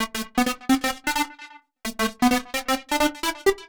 Index of /musicradar/uk-garage-samples/130bpm Lines n Loops/Synths